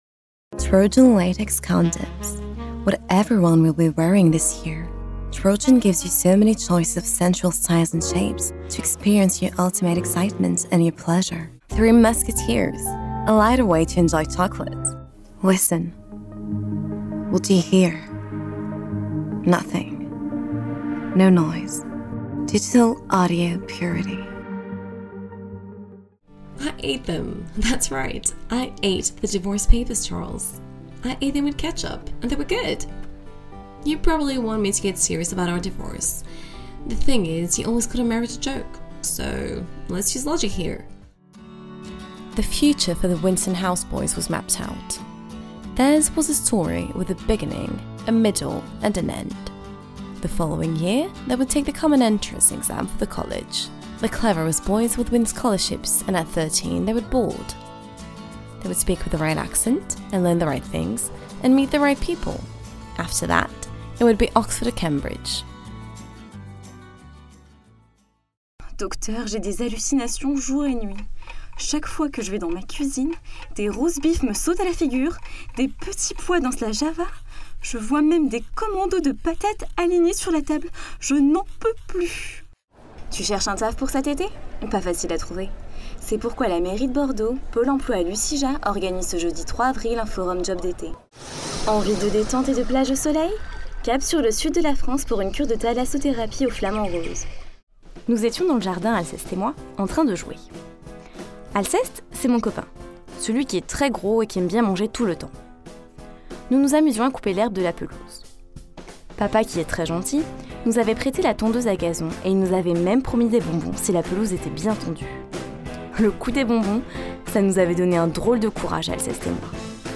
Demo
I work on Pro Tools First and Adobe Audition with a Rode NT1-A mic.